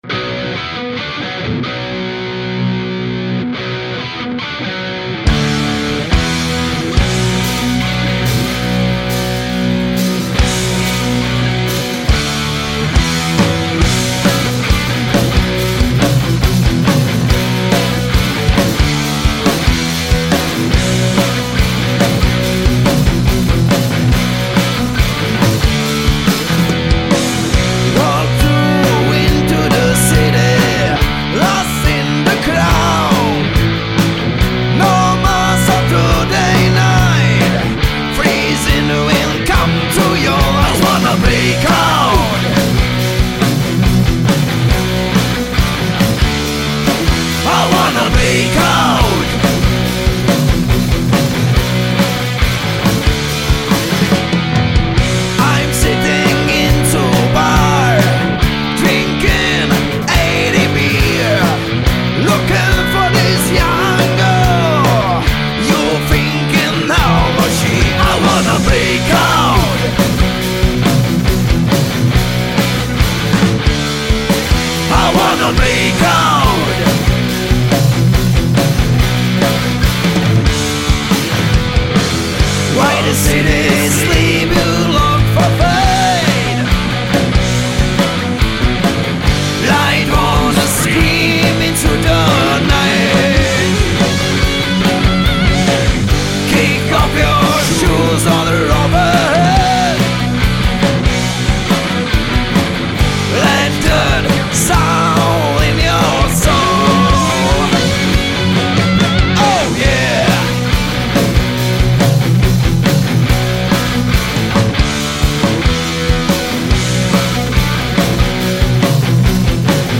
Žánr: Rock
Hard-rock 70-90.let s moderním soundem a aranžemi